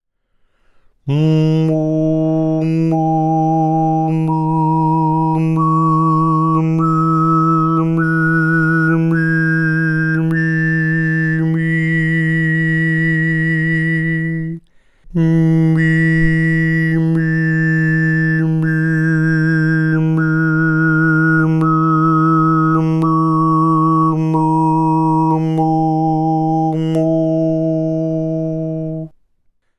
Hörprobe Obertonreihe Nr. 1 gesungen von H4 bis H12 mit Vokal-Technik
Bei den hier zu hörenden Aufnahmen bleibt der Grundton jeweils stabil auf einer sogenannten Grundton-Frequenz
obertonreihe-h4-h12-vokal.mp3